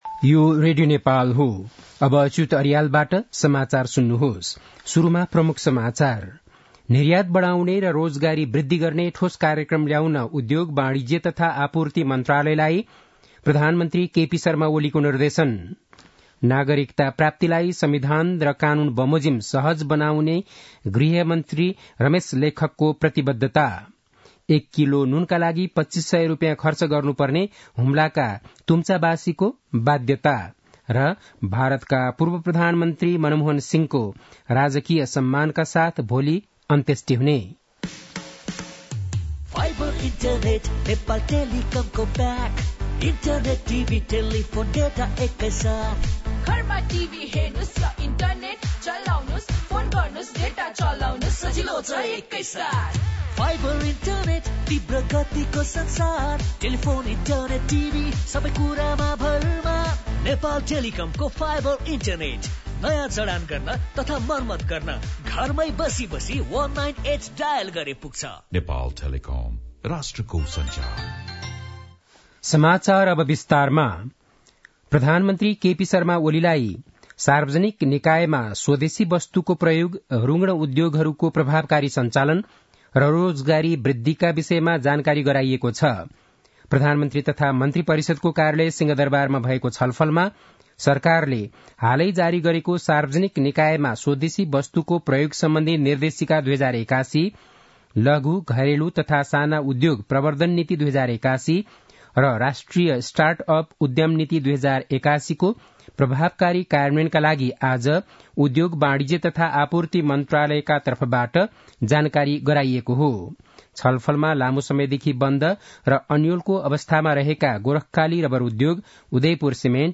बेलुकी ७ बजेको नेपाली समाचार : १३ पुष , २०८१